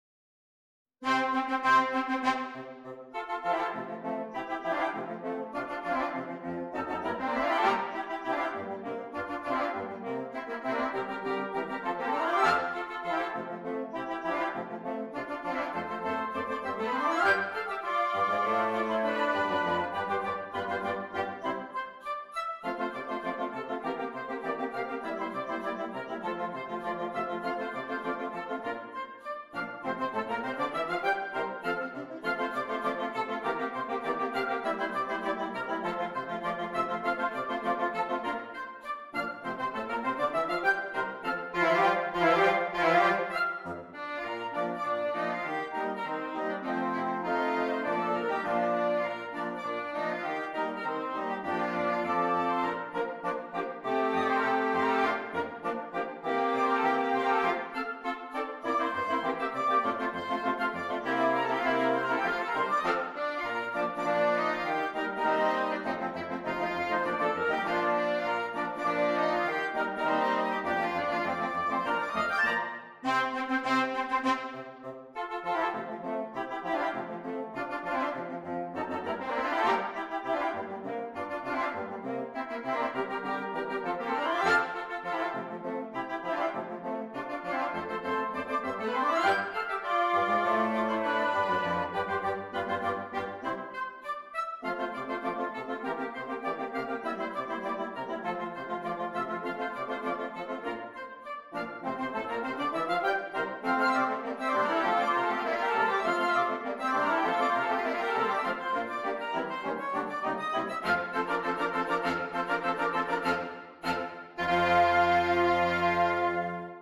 Interchangeable Woodwind Ensemble
Flashy and showy that sounds harder than it is!